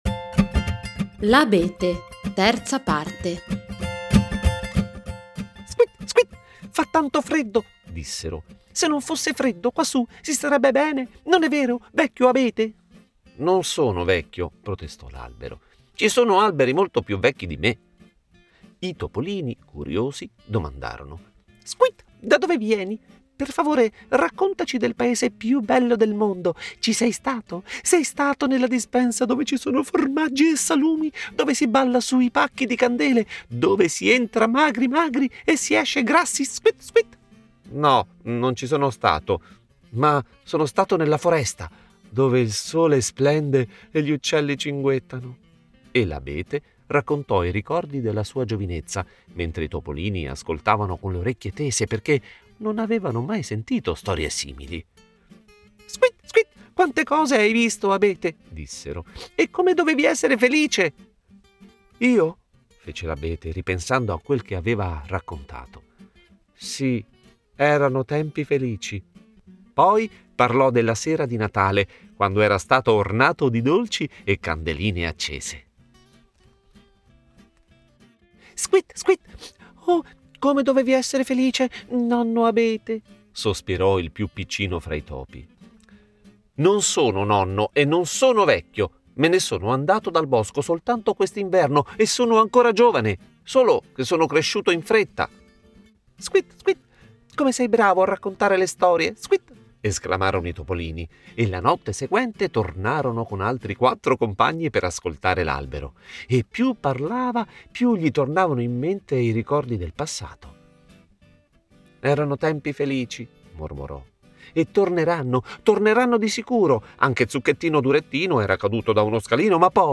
- L'abete - Parte 3 - Audiolibro con tappeto sonoro
abete-(con musica)-puntata-3.mp3